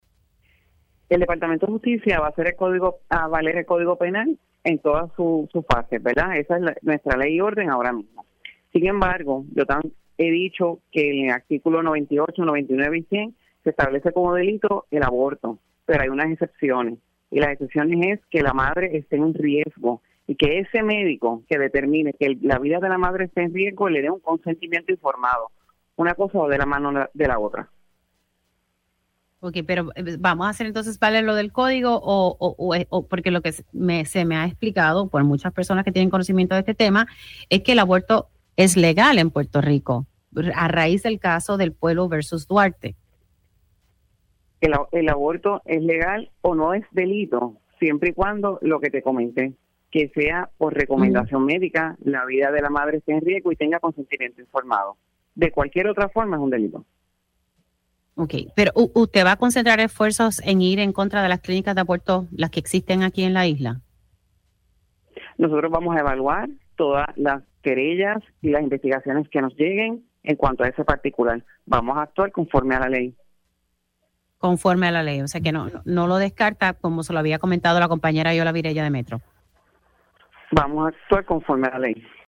La secretaria de Justicia, Lourdes Gómez enfatizó en Pega’os en la Mañana que el departamento actuará conforme al Código Penal en relación al aborto.